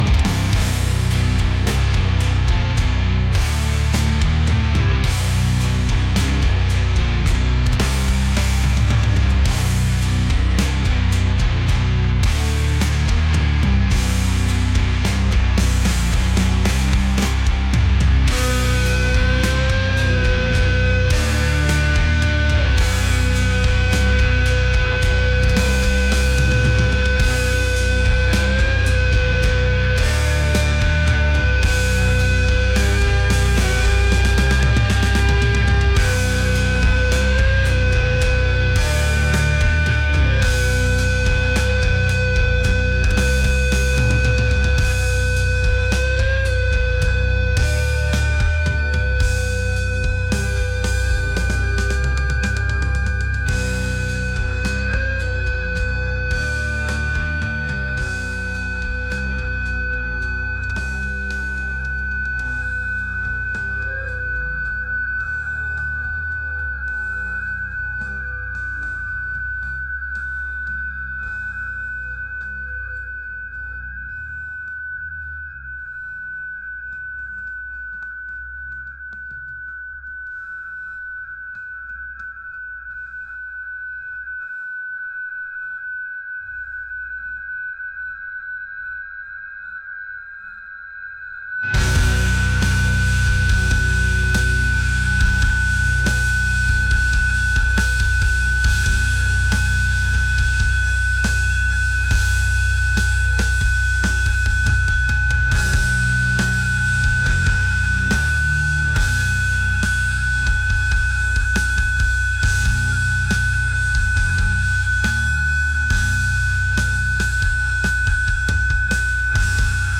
heavy | aggressive | metal